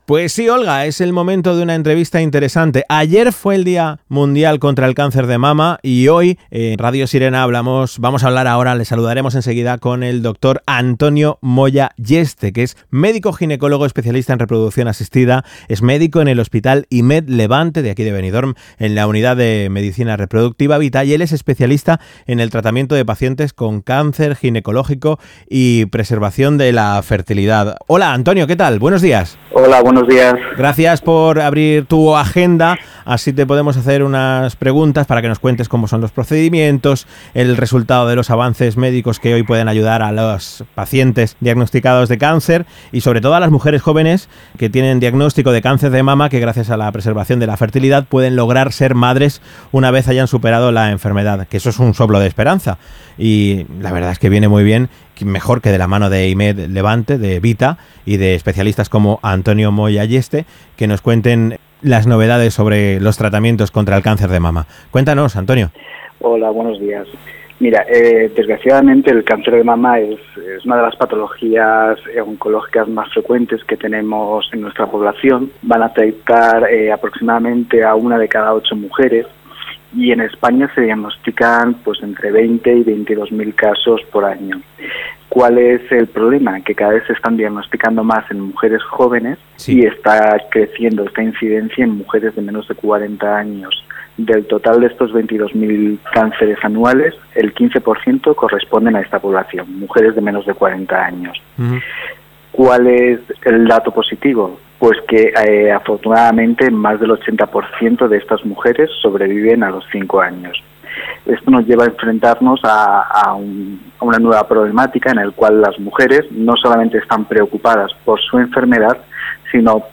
La entrevista fue realizada en COPE Benidorm (Radio Sirena), desde aquí nuestro agradecimiento.